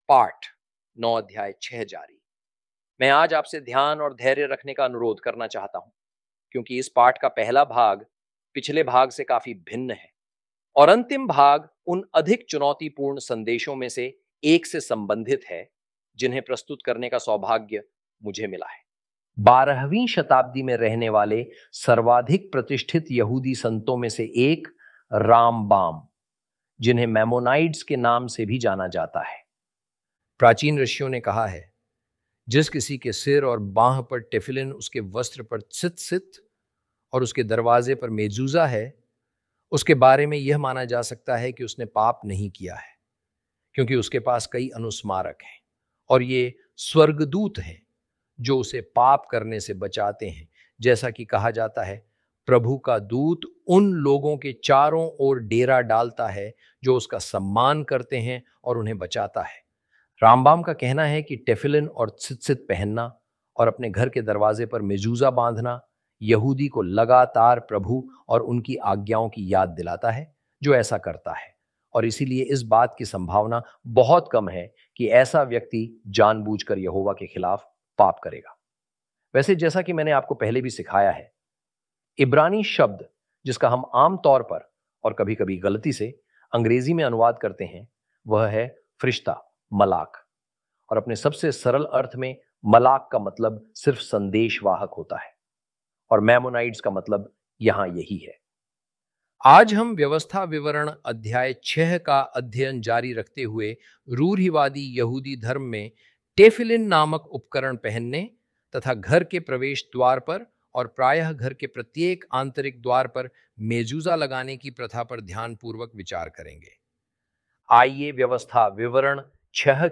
hi-audio-deuteronomy-lesson-9-ch6.mp3